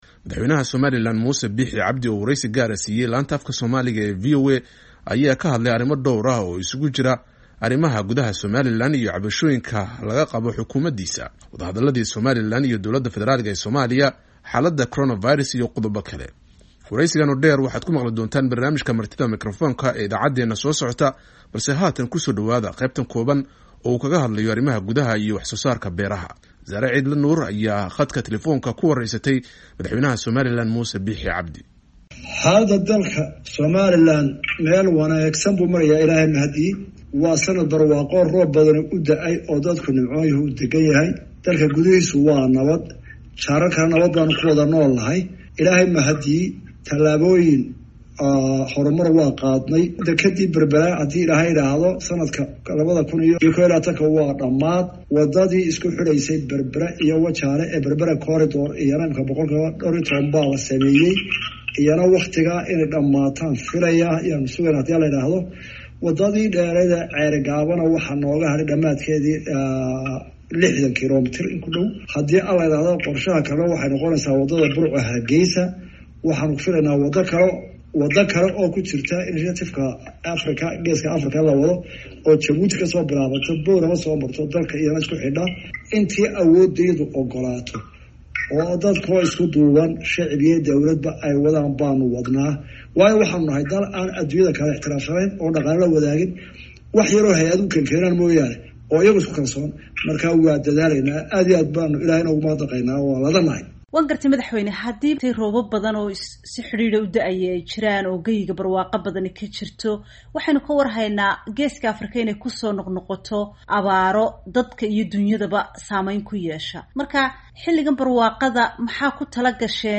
Halkaan ka dhageyso wareysiga Musa Biixi